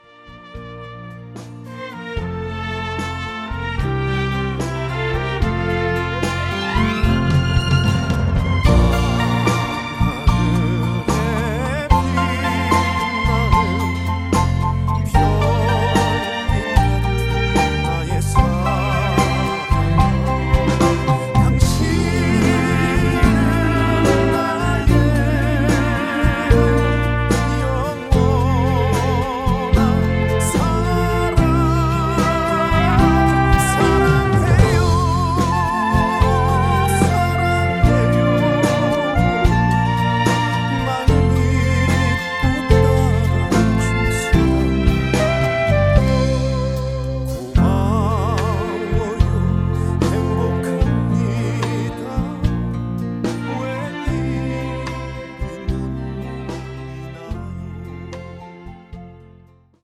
음정 -1키 3:51
장르 가요 구분 Voice MR